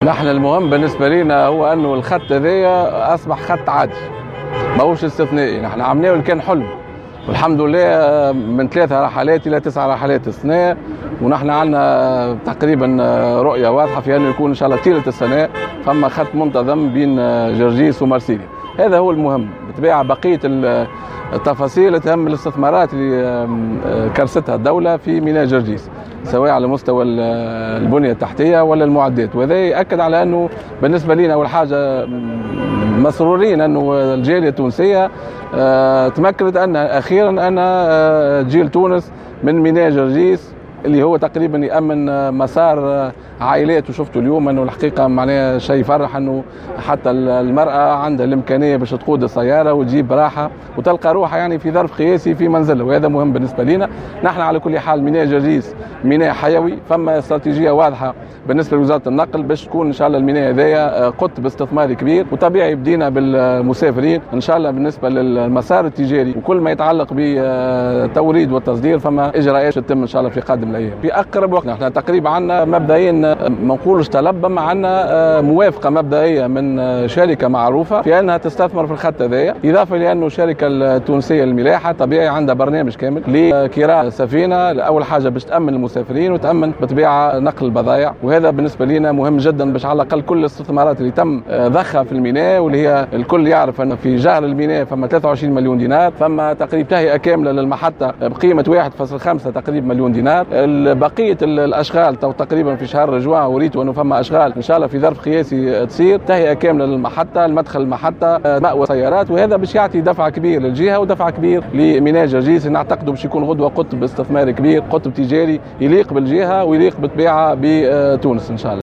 وأفاد وزير النقل رضوان عيارة في تصريح لمراسلة الجوهرة اف ام بالجهة، أن عدد الرحلات القادمة من مرسيليا إلى جرجيس، سيرتفع هذه السنة إلى 9 رحلات، مقابل 3 رحلات السنة الماضية، مؤكدا سعي الوزارة إلى مزيد تدعيم الخط طيلة السنة.